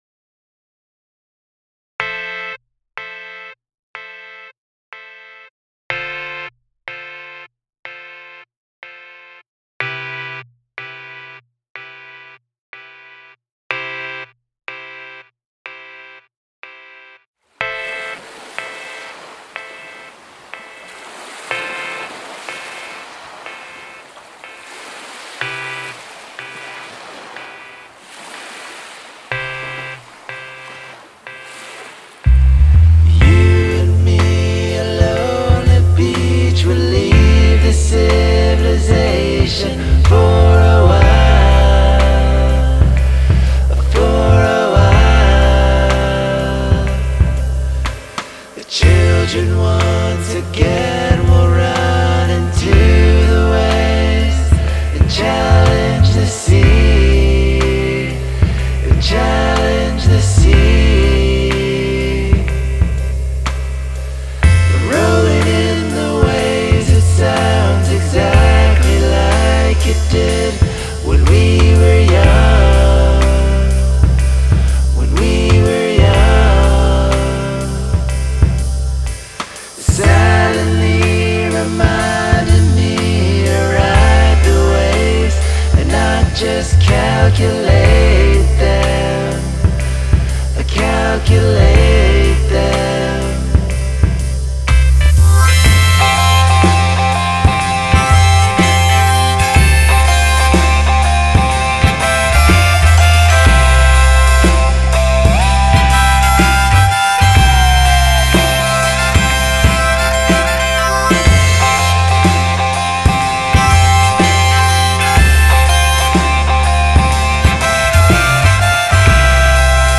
Hey all, New track, supposed to invoke lazy, relaxed, beach-induced grooves.